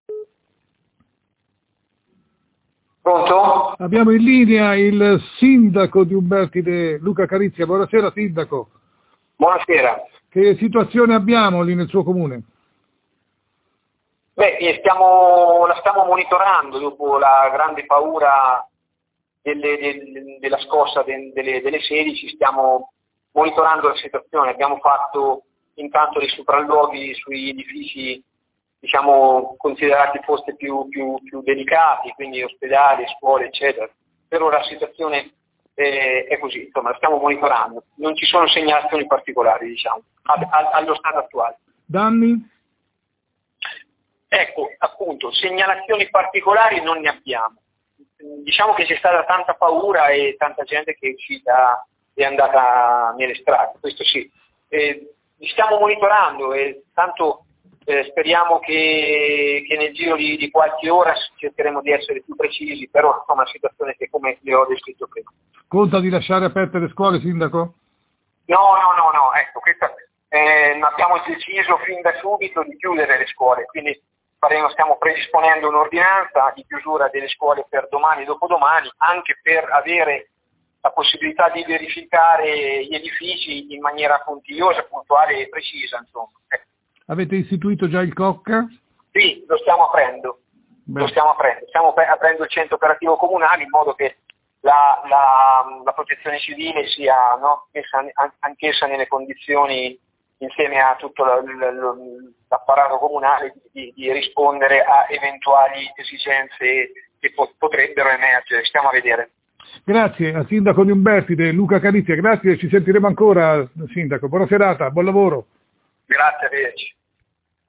Il sindaco Luca Carizia
sindaco-carizia.mp3